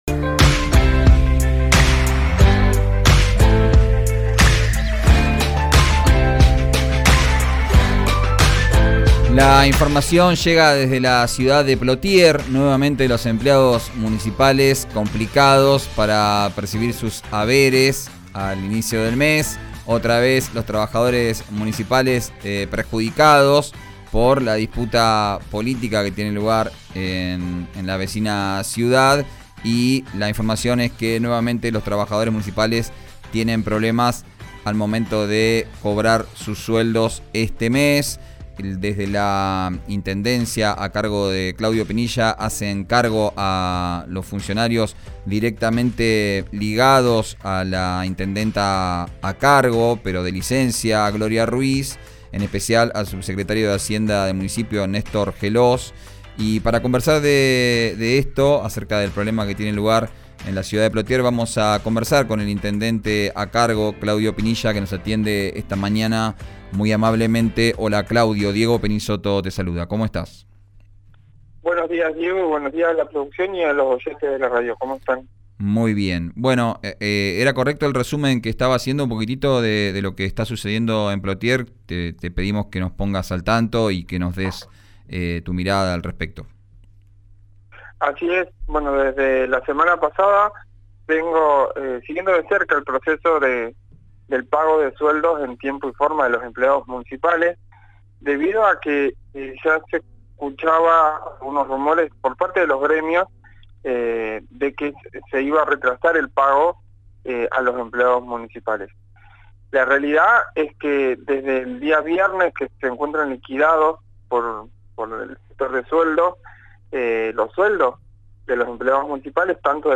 Escuchá la entrevista completa a Claudio Pinilla, intendente interino de Plottier, en «Vos al aire», el nuevo programa de RÍO NEGRO RADIO.